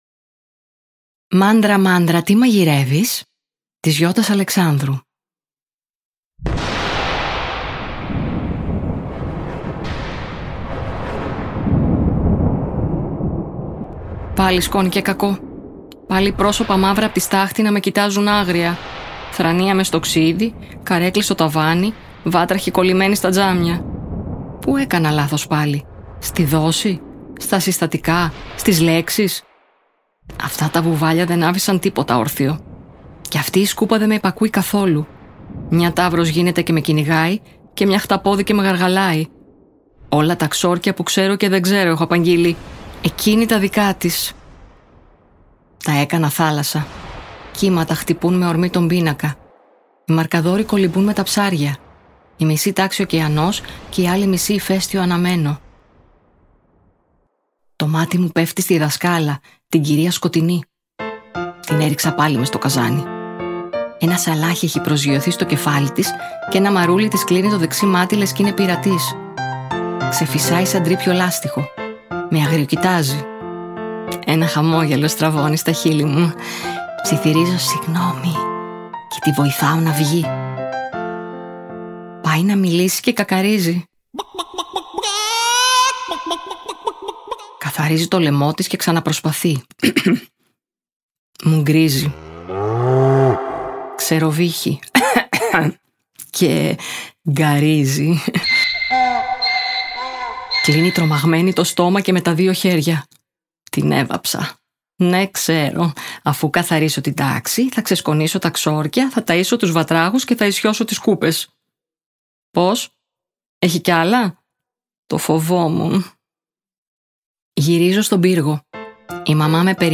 Περιέχει QR CODE με την αφήγηση